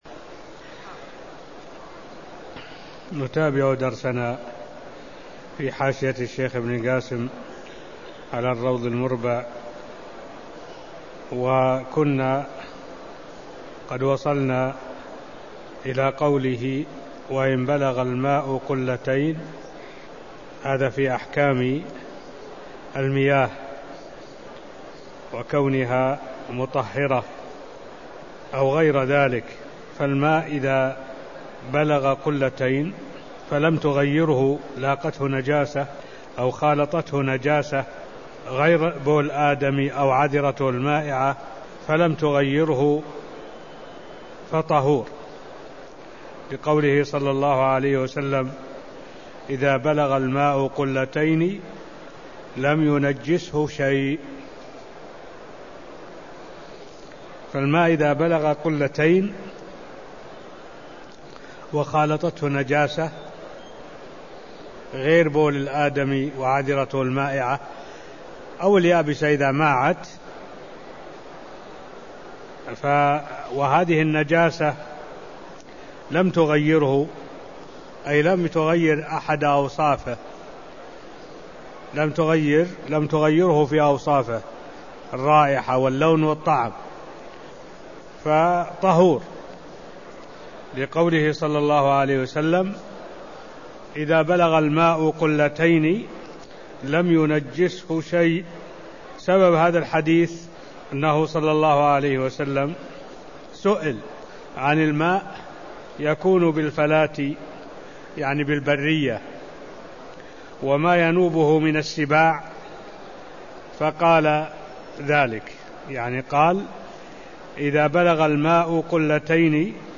المكان: المسجد النبوي الشيخ: معالي الشيخ الدكتور صالح بن عبد الله العبود معالي الشيخ الدكتور صالح بن عبد الله العبود باب اذا بلغ الماء قلتين فما بعده (0013)